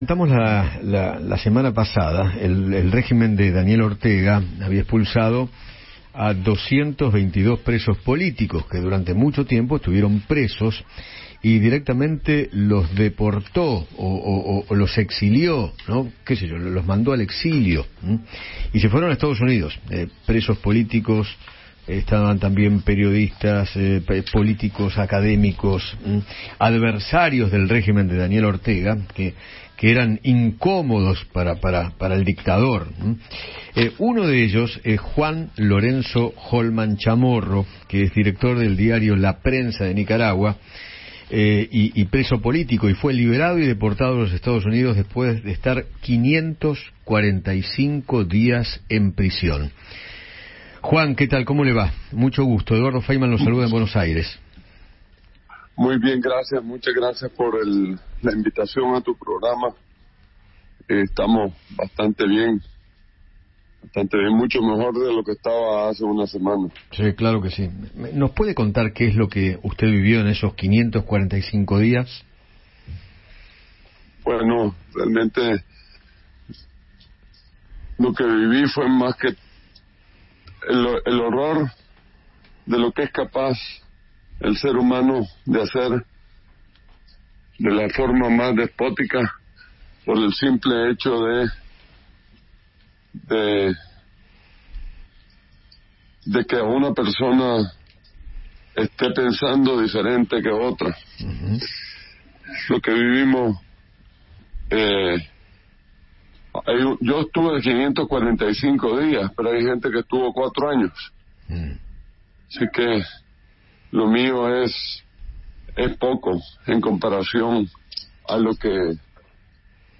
conversó con Eduardo Feinmann sobre los 222 presos políticos que fueron expulsados de Nicaragua por decisión de Daniel Ortega.